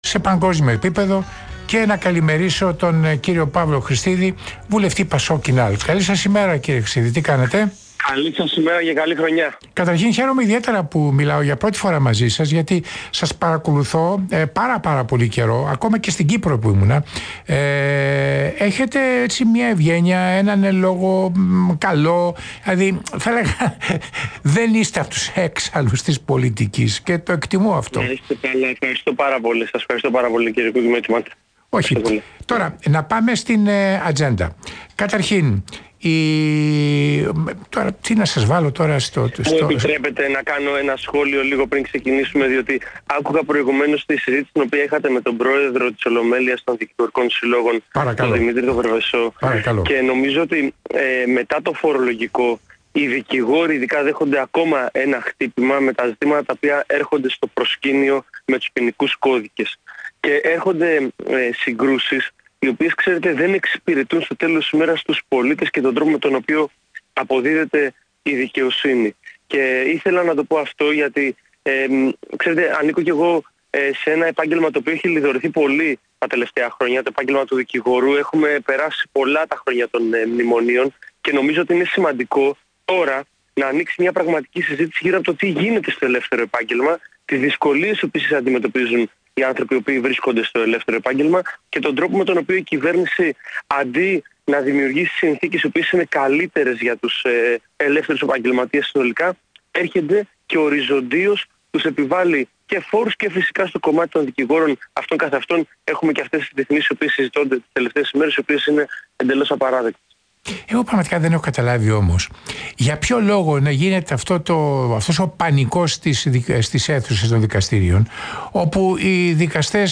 Συνέντευξη στον Real fm και τον Τέρενς Κουίκ